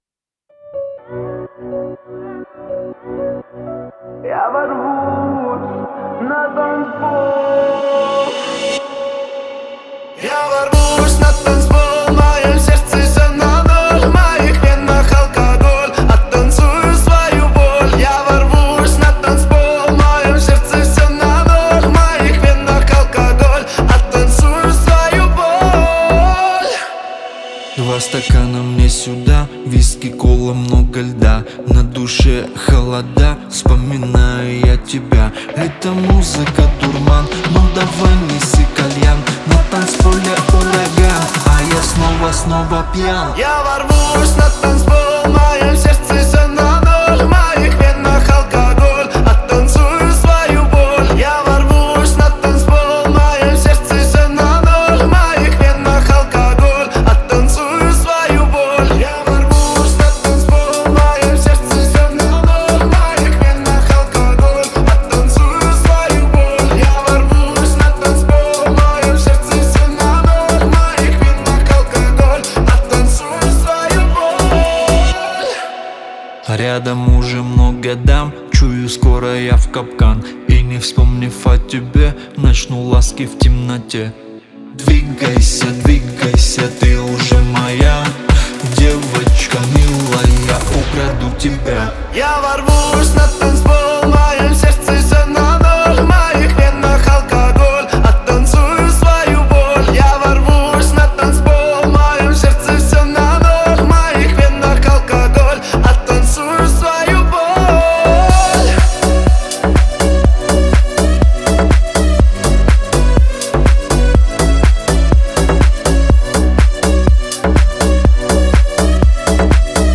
это зажигательная композиция в жанре поп-музыки